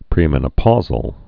(prēmĕn-ə-pôzəl)